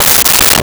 Toilet Paper Dispenser 05
Toilet Paper Dispenser 05.wav